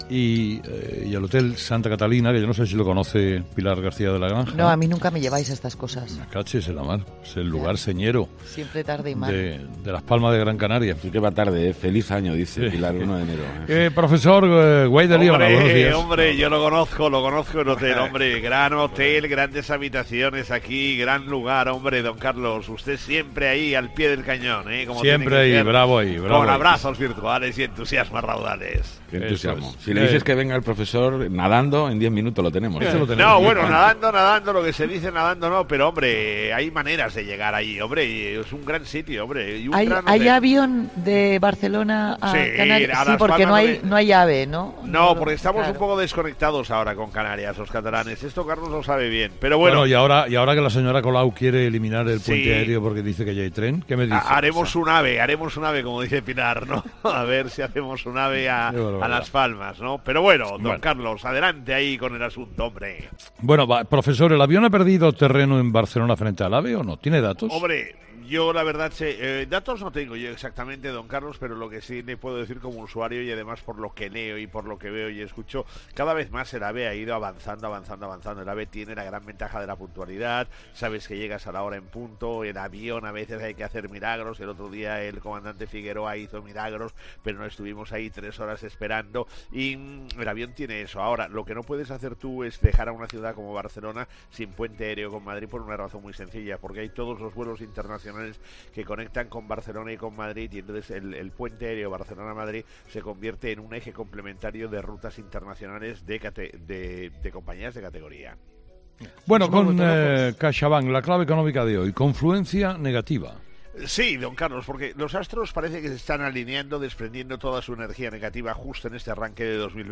La actualidad económica en 'Herrera en COPE' con el profesor Gay de Liébana.